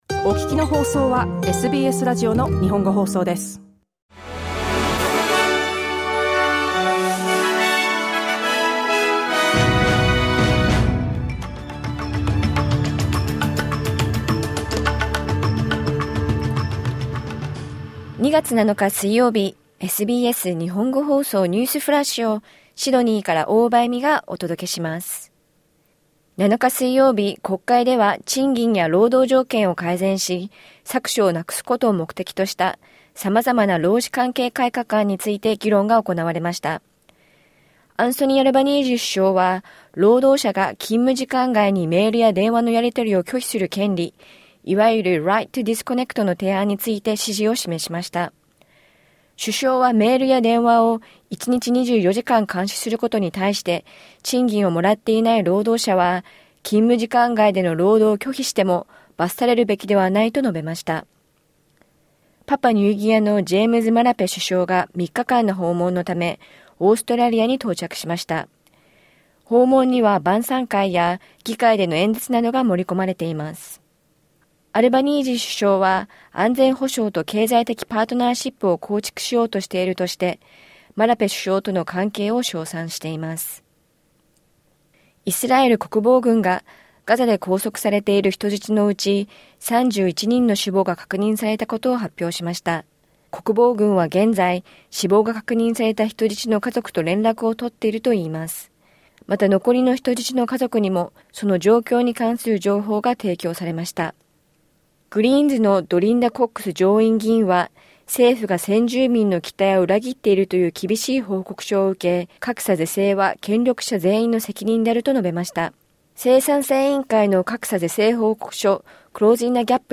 SBS日本語放送ニュースフラッシュ 2月7日水曜日